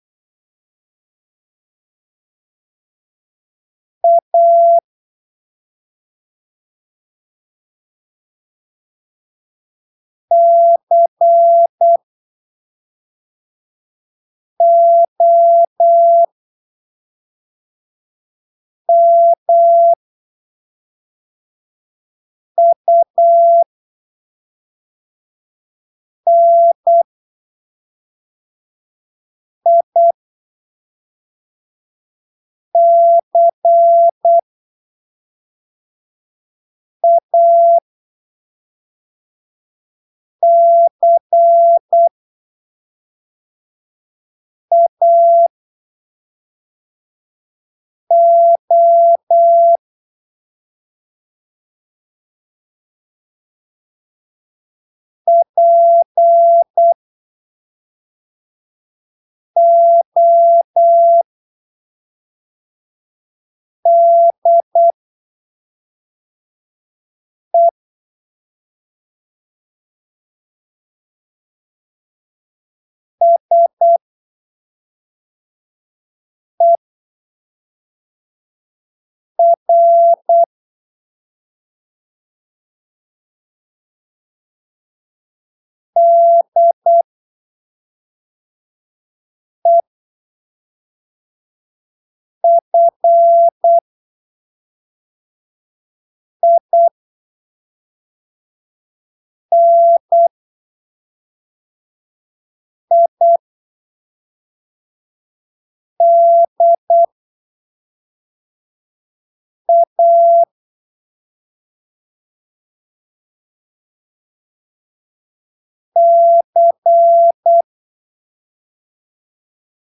em em uma velocidade aproximada de "8ppm" com um espaçamento
bem generoso entre os caracteres. Você vai encontrar o texto em
Texto CW em áudio  18               Texto correção 18